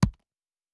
pgs/Assets/Audio/Fantasy Interface Sounds/UI Tight 02.wav at master
UI Tight 02.wav